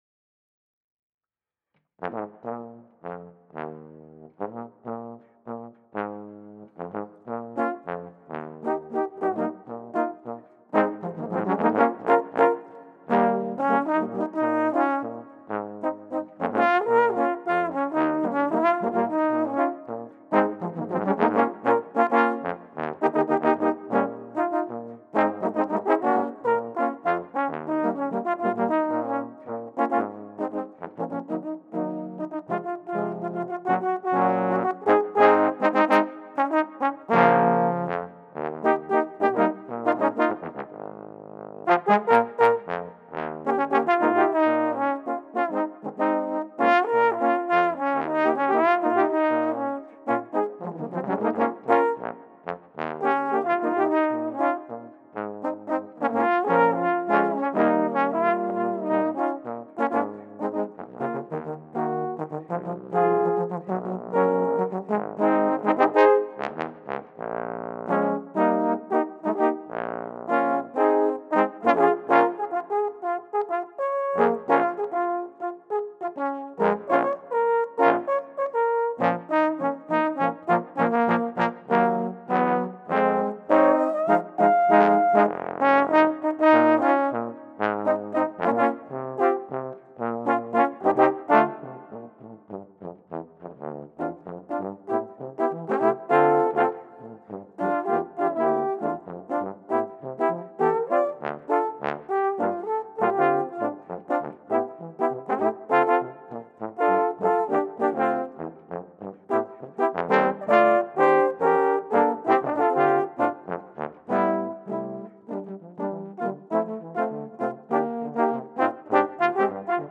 for Trombone Quartet
A brand-new, original number for trombones.